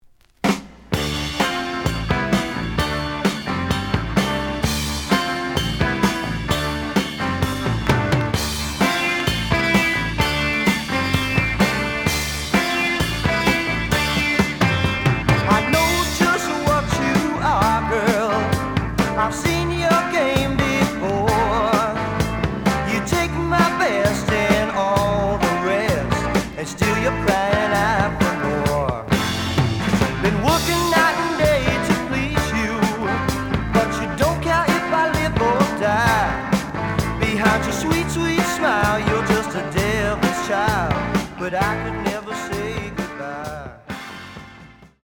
試聴は実際のレコードから録音しています。
●Format: 7 inch
●Genre: Rock / Pop
傷は多いが、プレイはまずまず。)